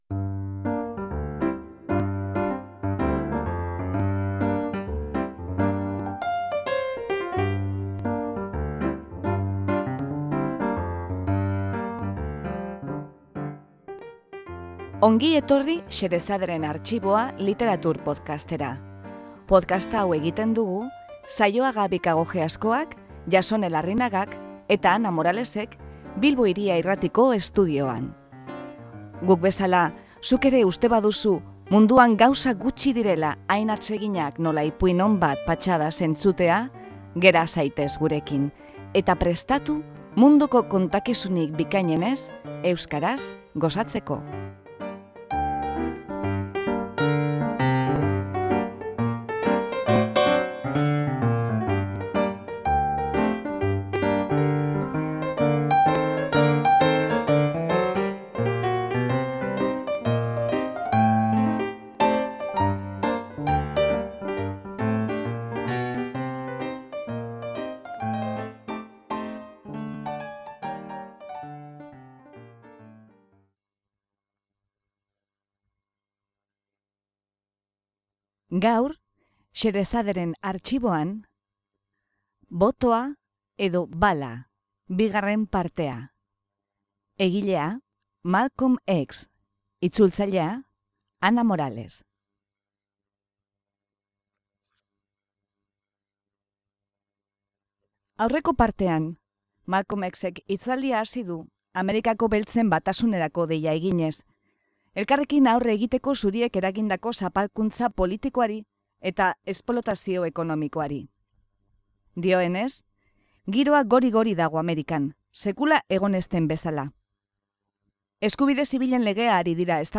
Malcolm Xen ustez, Estatu Batuetako demokrazia ez da benetakoa. Hori dela-eta, eskubide zibilen alde borrokatu beharrean, giza-eskubideen alde borrokatu behar da. Hitzaldiaren bigarren zatia.